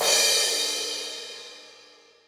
Cymbal
Original creative-commons licensed sounds for DJ's and music producers, recorded with high quality studio microphones.
Drum Crash Sample F# Key 13.wav
long-single-cymbal-hit-f-sharp-key-02-Y28.wav